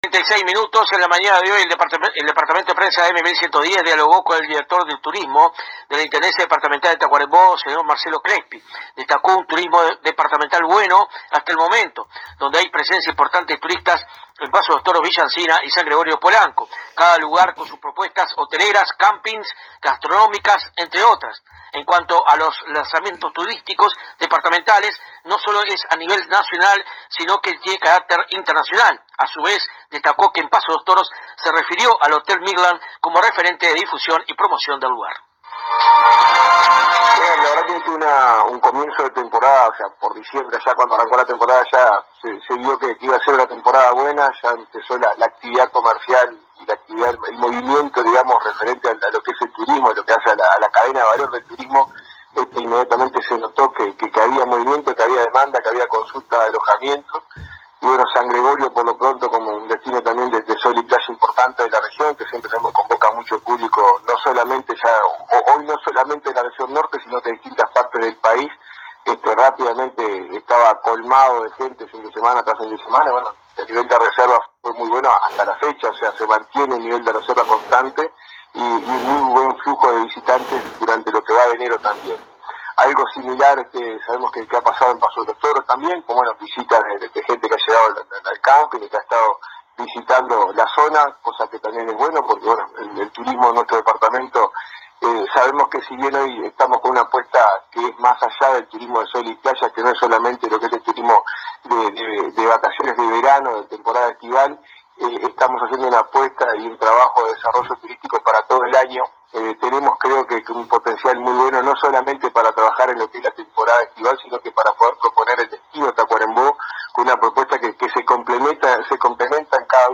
El Director de Turismo de la Intendencia de Tacuarembó, Marcelo Crespi, ofreció declaraciones a la AM 1110 de nuestra ciudad, en las cuales se refirió al buen desempeño en el aspecto turístico que han tenido los tres principales destinos de sol y playa de nuestro departamento: San Gregorio de Polanco, Villa Ansina y Paso de los Toros.